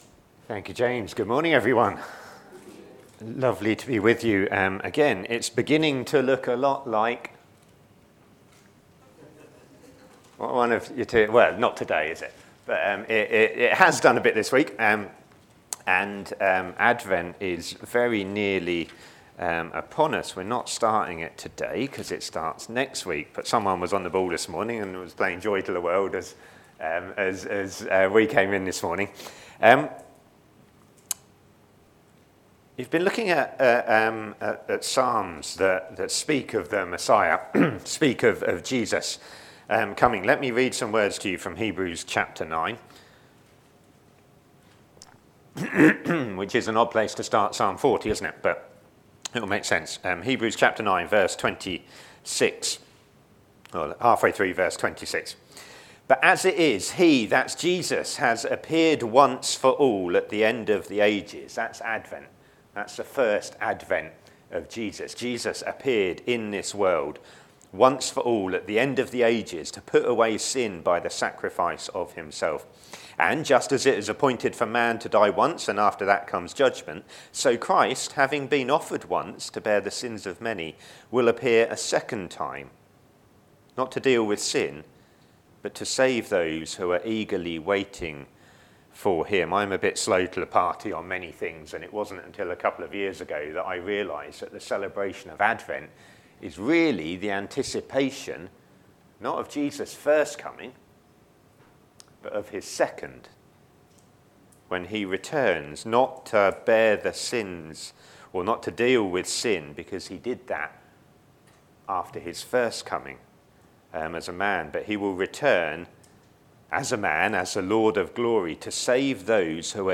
Media for a.m. Service on Sun 24th Nov 2024 10:30
Passage: Psalm 40 Series: Psalms and the Saviour Theme: Sermon